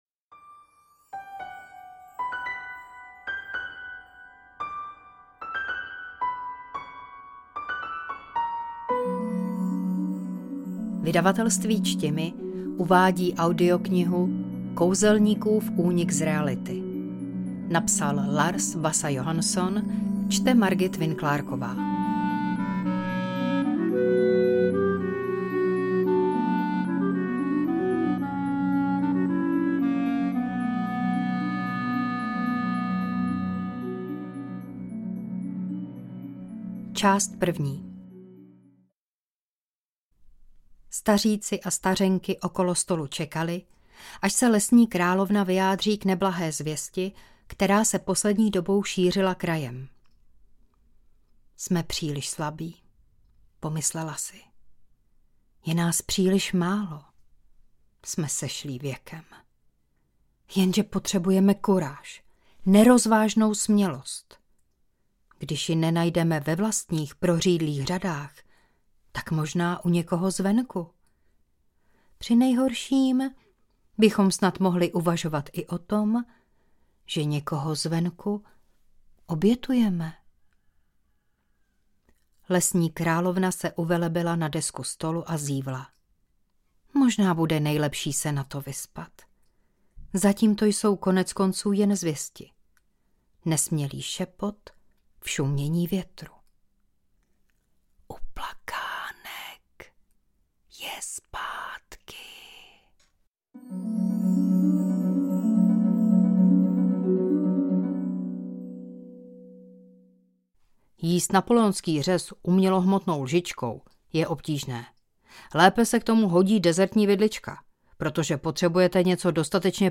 AudioKniha ke stažení, 56 x mp3, délka 11 hod. 21 min., velikost 627,4 MB, česky